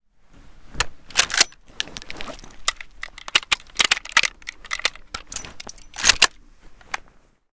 mosin_reload.ogg